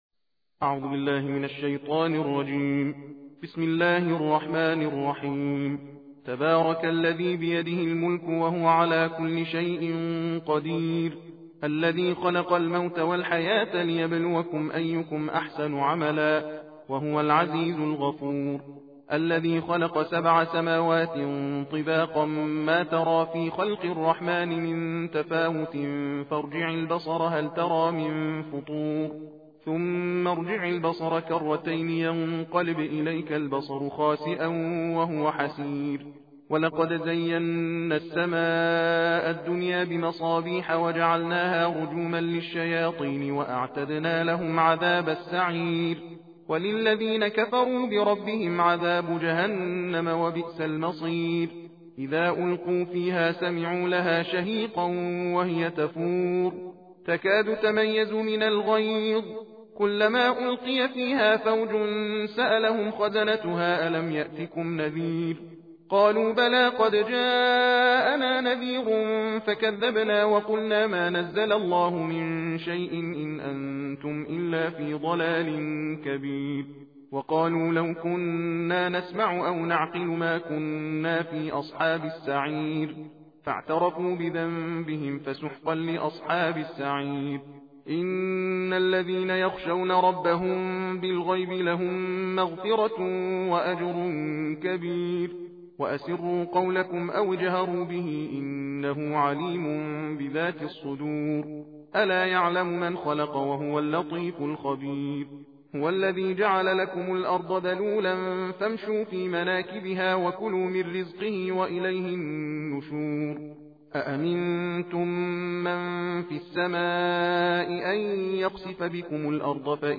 تندخوانی جزء بیست و نهم قرآن کریم